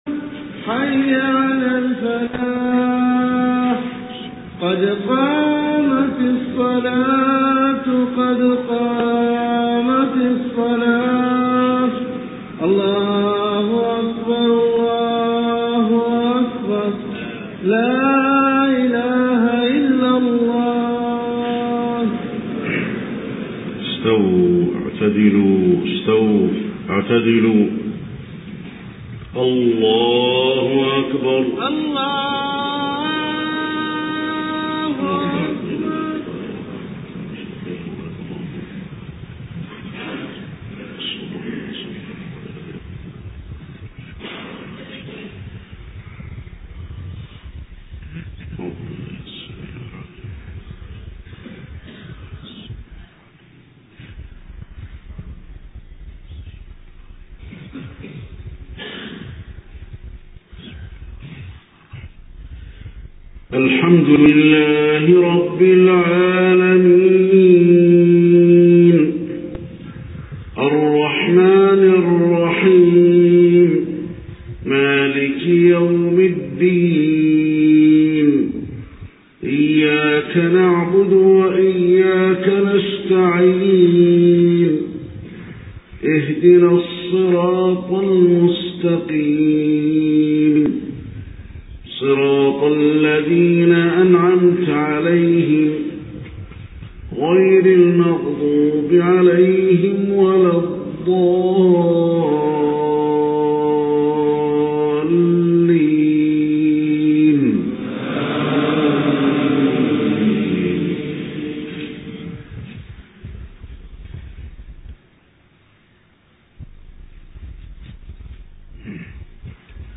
صلاة الفجر 21 محرم 1430هـ سورة المرسلات كاملة > 1430 🕌 > الفروض - تلاوات الحرمين